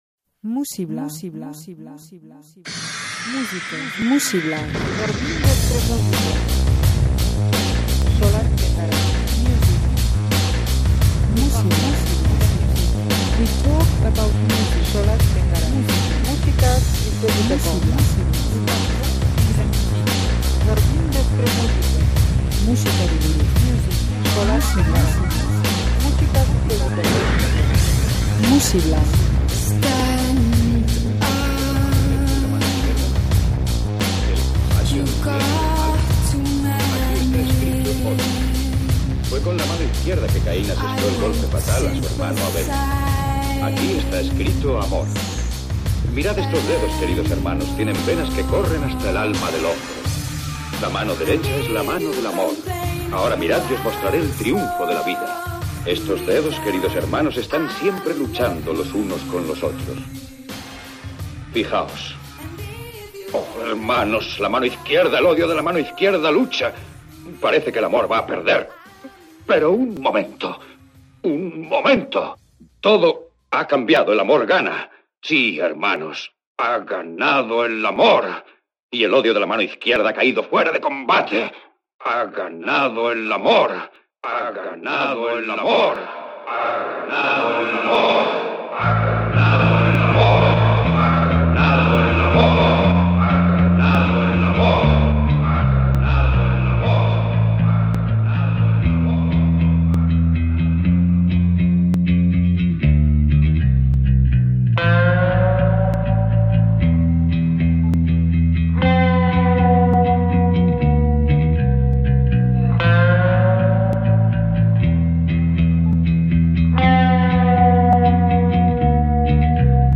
Giro ilunekin hasiko dugu gaurko saioa
giro ilunak eta atmosfera beteak